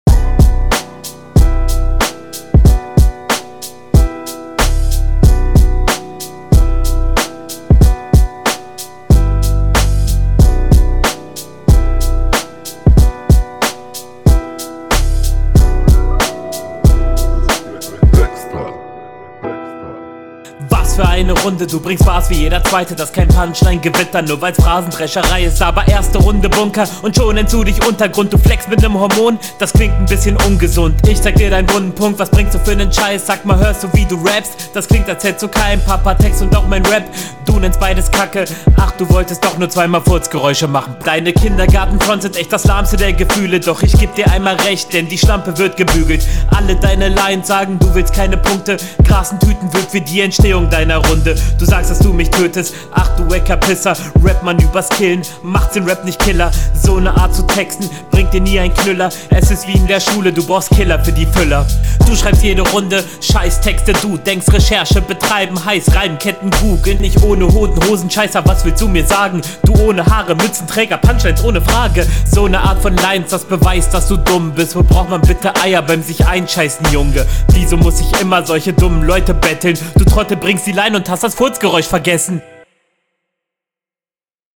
Erste Line schon ein Volltreffer und in nem coolen Reim verpackt, alles was danach kommt …